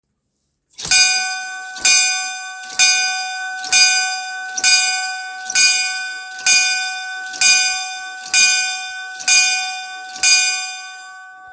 bell-audio.m4a